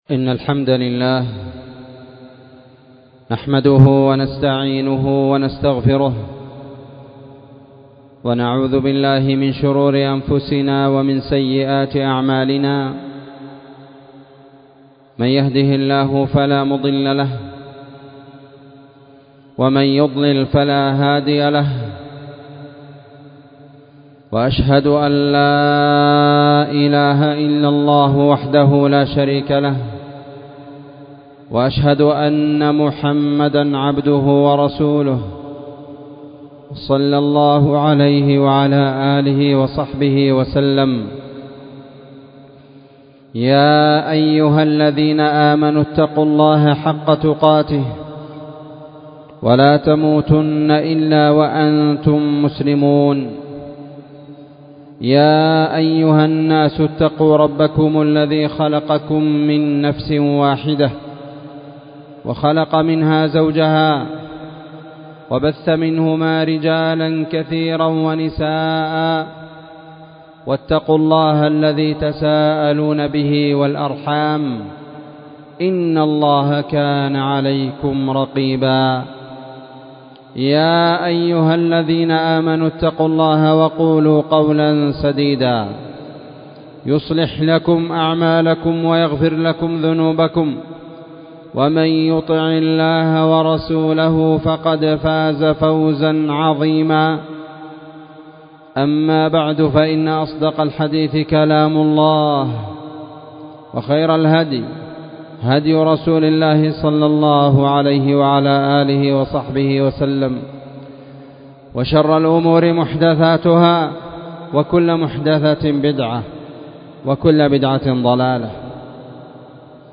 خطبة
في مسجد المجاهد- تعز